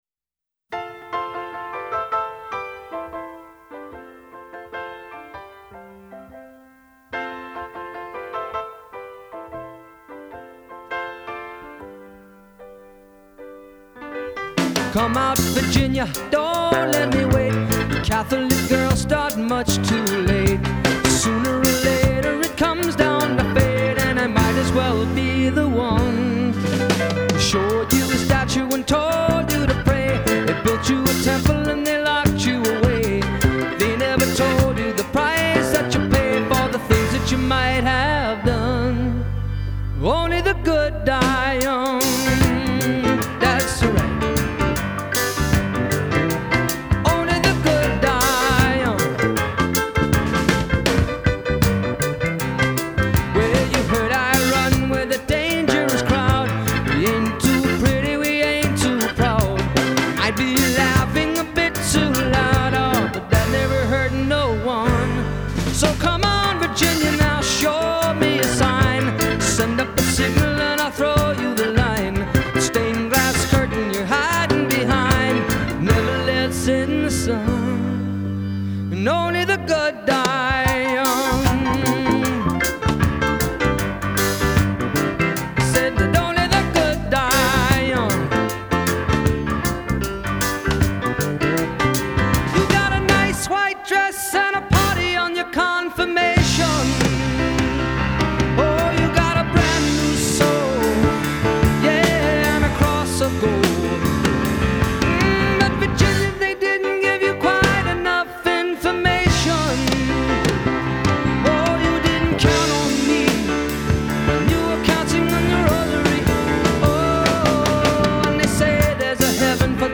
playful reggae version
my remastering here sounds a lot better